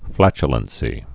(flăchə-lən-sē)